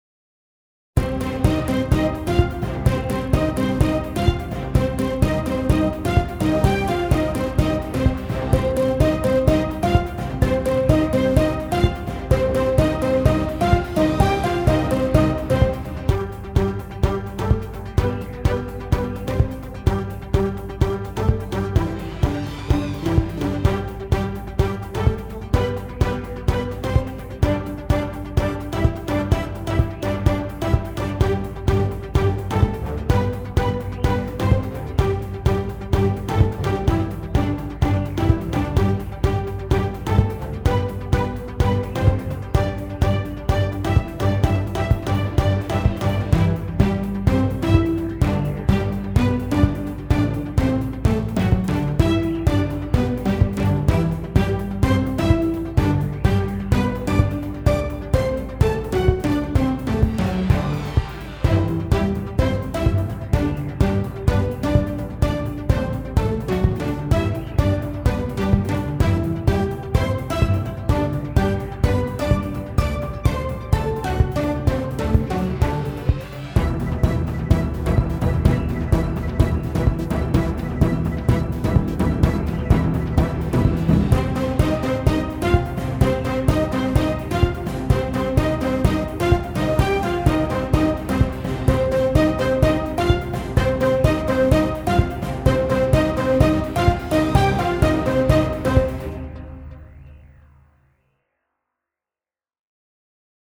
Upbeat retro track for brawl mayhem